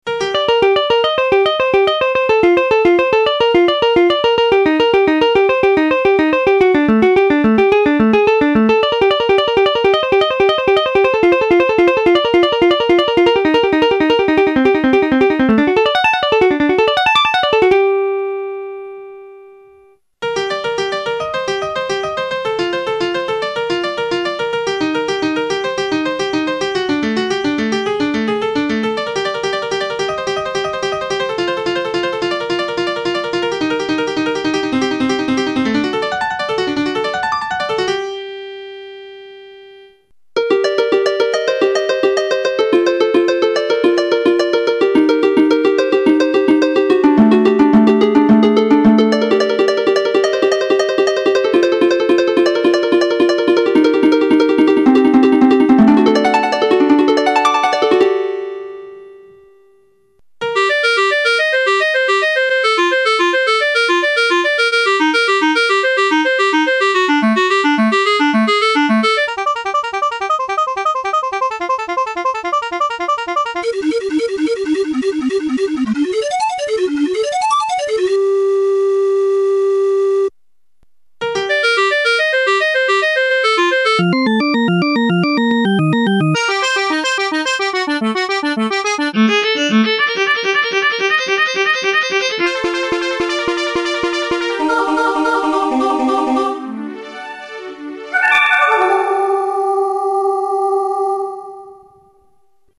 These are some audio demos using the G2M with a guitar. Various licks were recorded as MIDI then played back using different MIDI sounds to let you hear what can be acheived just by changing the sound.
G2M_Licks3.mp3